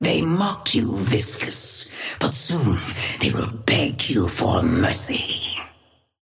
Sapphire Flame voice line - They mock you, Viscous, but soon they will beg you for mercy.
Patron_female_ally_viscous_start_08.mp3